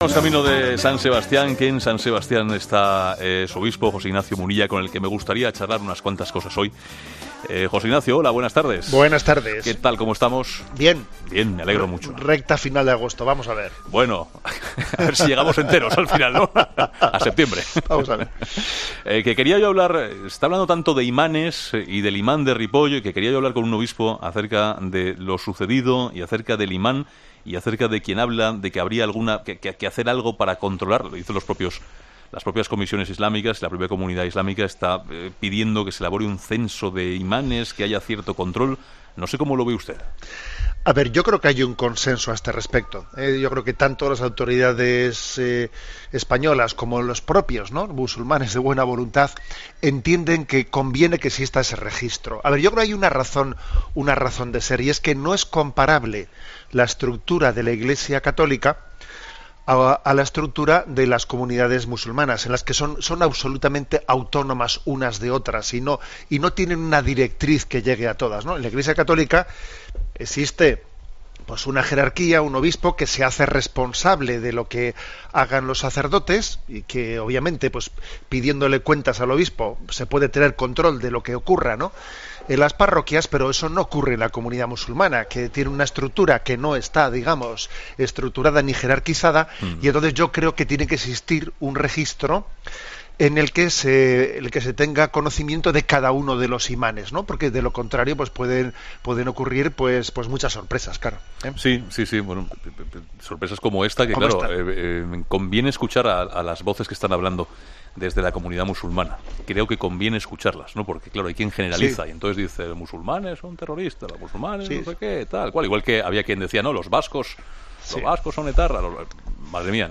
El obispo de San Sebastián, José Ignacio Munilla , ha vuelto a 'La Tarde' para hablar sobre la formación que reciben los imanes en nuestro país y cómo se debería actuar para casos cómo el de Ripoll no vuelvan a suceder. ESCUCHA LA ENTREVISTA COMPLETA | El obispo de San Sebastián, José Ignacio Munilla “ Creo que tanto las autoridades españolas cómo los propios musulmanes entienden que conviene ese registro ” afirma Monseñor Munilla.